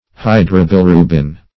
Hydrobilirubin \Hy`dro*bil`i*ru"bin\, n. [Hydro-, 2 +